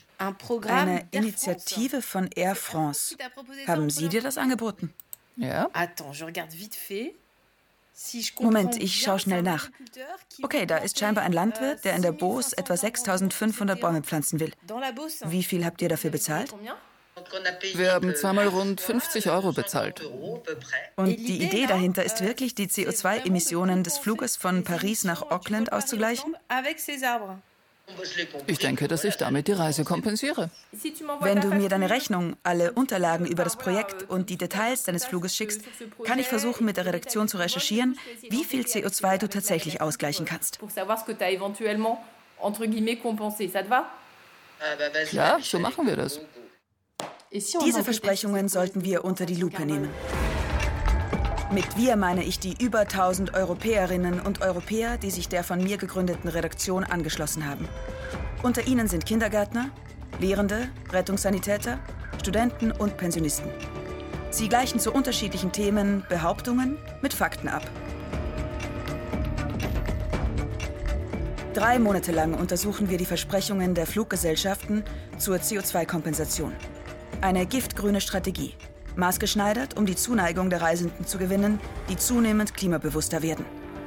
Dokumentation - Traunfall Die Einsamkeit der Ersten ihrer Art (Ö1) Arson (Ö1) Kinderhotel Energieanbieter WAS WIRKLICH GESCHAH (Hörspiel; alle Charaktere) Chicken or Pasta? (Ö1) Reportage/Overvoice (ARTE) Reportage (ORF) Kleidung (Deutschland) Schokolade (Österreichisch) Schokolade (Salzburgerisch)